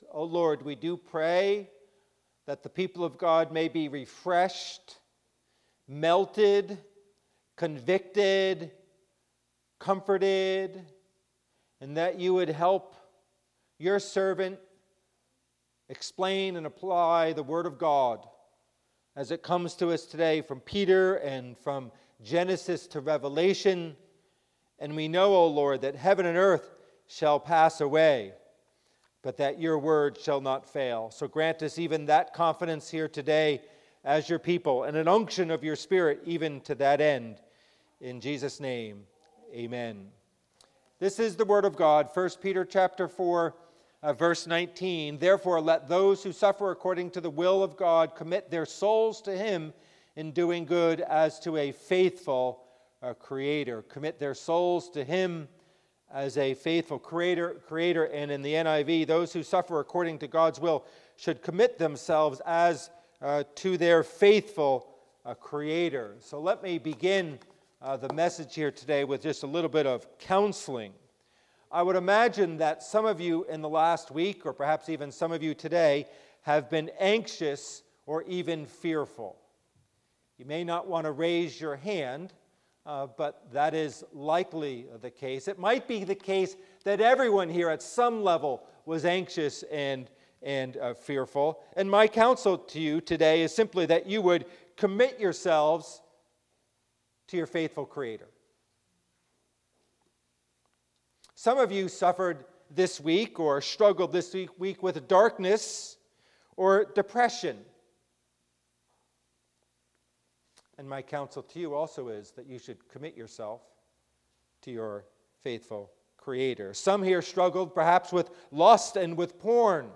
Passage: 1 Peter 4:19 Service Type: Worship Service